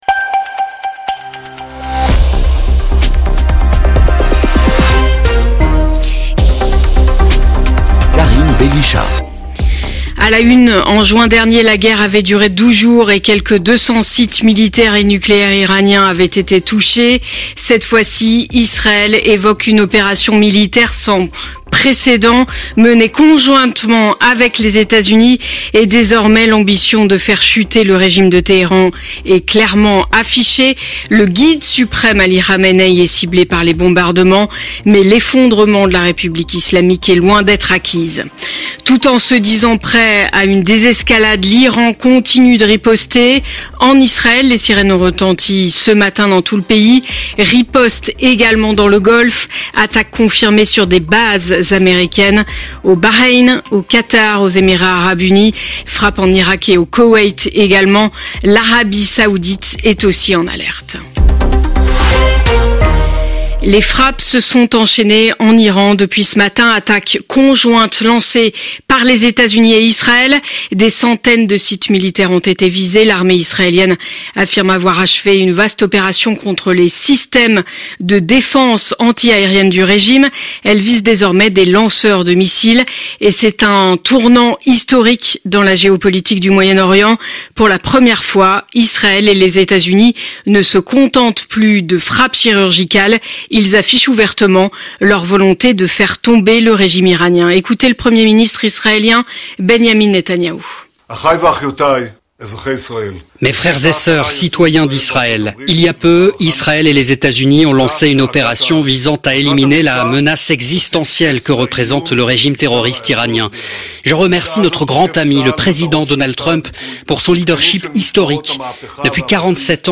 Flash infos 28/02/2026
L'infos sur radio COOL DIRECT de 7h à 21h